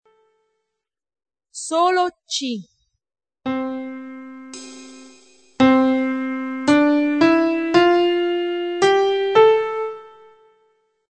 Qualora gli mp3 (di 2ª qualità per non appesantire il sito), di questa pagina, non fossero perfetti nell'ascolto, scriveteci, Vi invieremo sulla vostra casella di posta i file di 1ª qualità, gratuitamente.